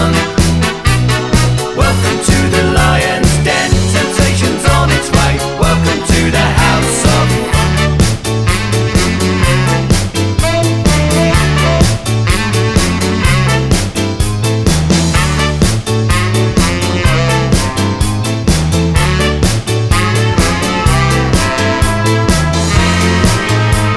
No Backing Vocals Ska 2:51 Buy £1.50